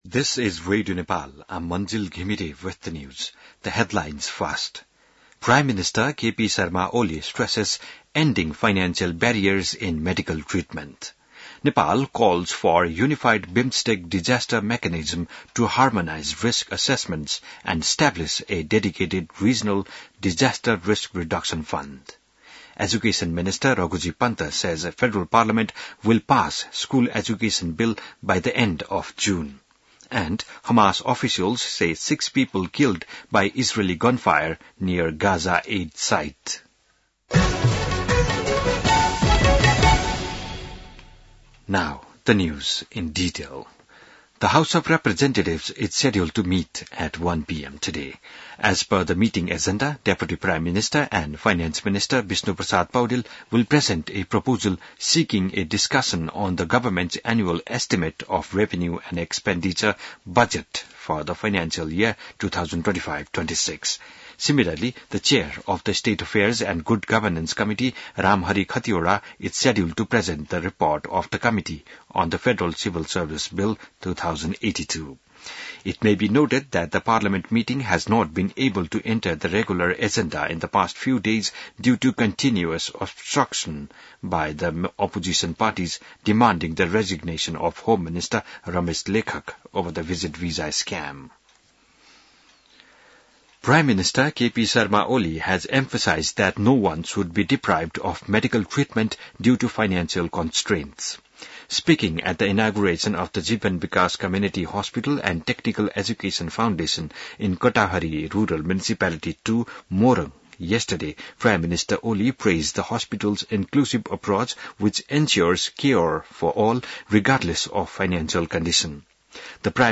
बिहान ८ बजेको अङ्ग्रेजी समाचार : २५ जेठ , २०८२